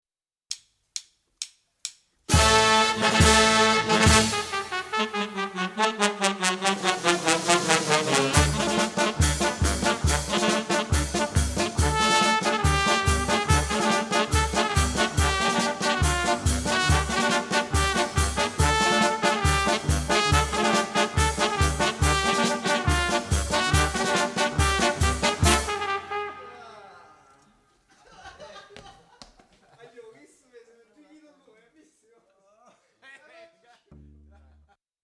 sax soprano, voce
sax tenore
sax baritono
tromba
trombone
chitarra elettrica
contrabasso
batteria
piatti e fracasso
cori
all'"Executive Studios" di Napoli nel marzo 1990